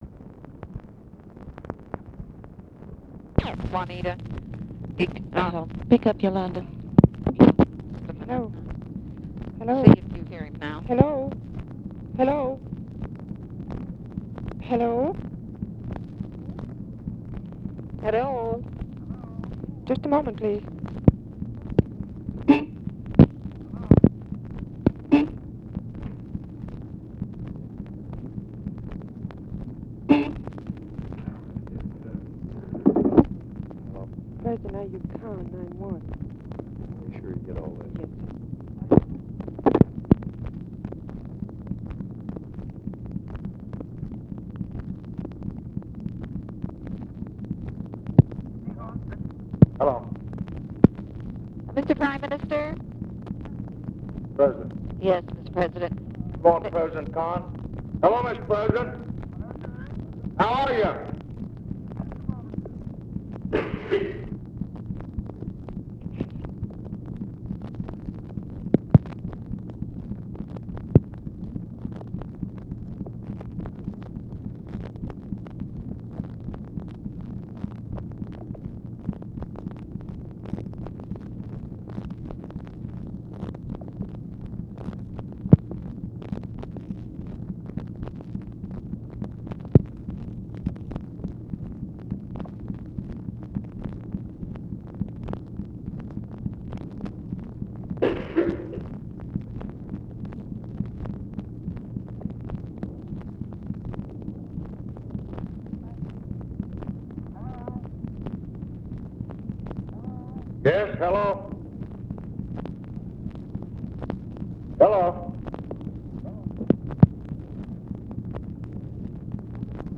OFFICE CONVERSATION ABOUT FOREIGN AID BILL, AID FOR INDIA-PAKISTAN, UNPOPULARITY OF FOREIGN AID IN CONGRESS; LBJ SPEAKS WITH LONDON TELEPHONE OPERATOR ATTEMPTING TO GET BETTER CONNECTION; KHAN DISCUSSES INDIA- PAKISTAN CEASEFIRE IN KASHMIR